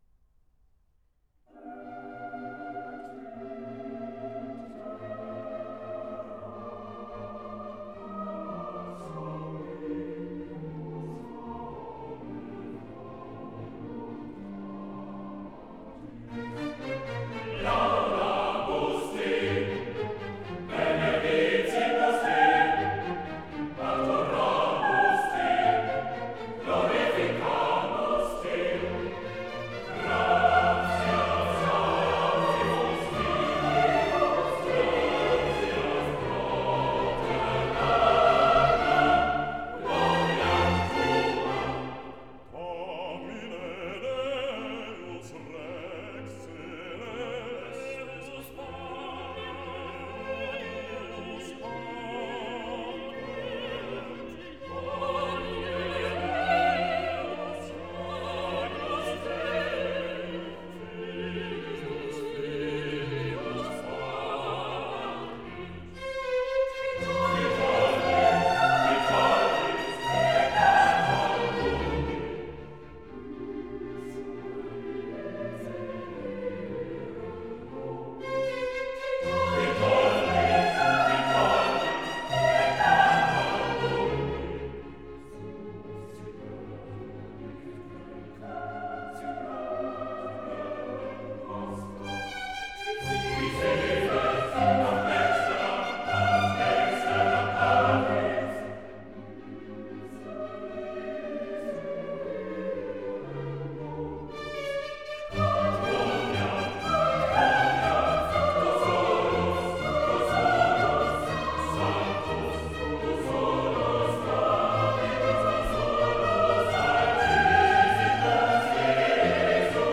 » nhac-khong-loi